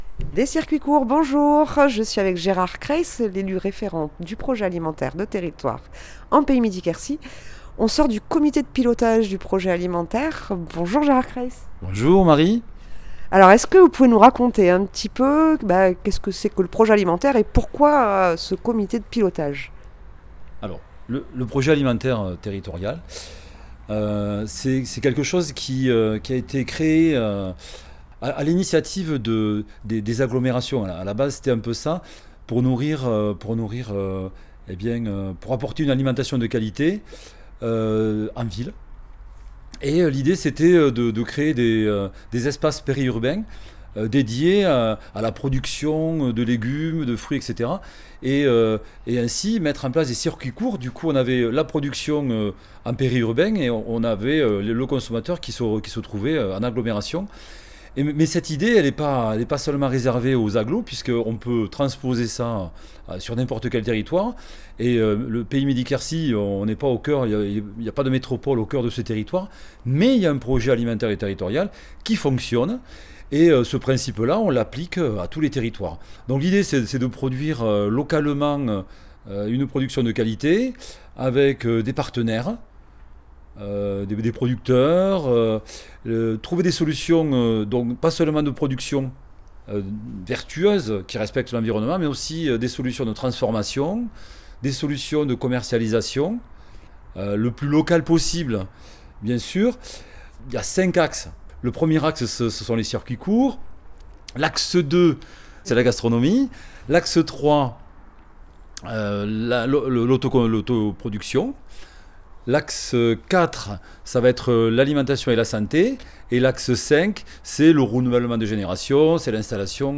Invité(s) : Gérard Craïs, élu référent du PAT du Pays Midi-Quercy